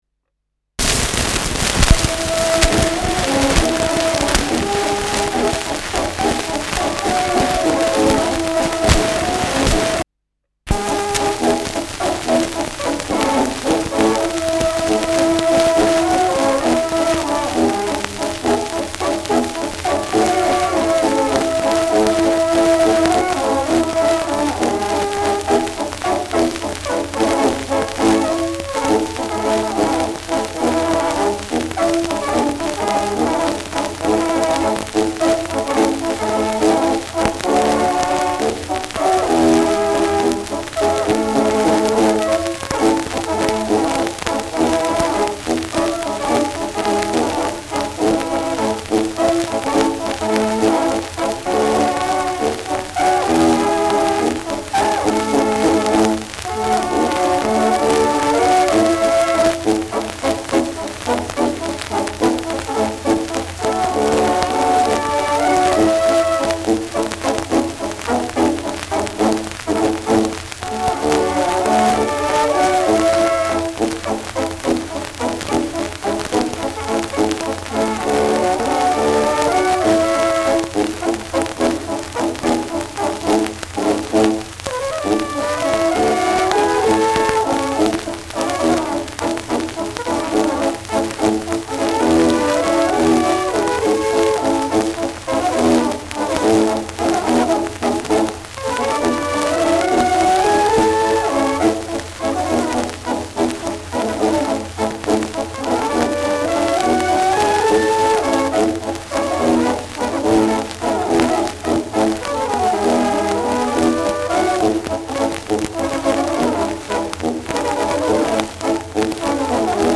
Schellackplatte
„Hängen“ bei 0’09’’ : starkes bis präsentes Rauschen : abgespielt : leiert : präsentes Knistern : häufiges Knacken
Andorfer Bauernkapelle (Interpretation)
[Wels] (Aufnahmeort)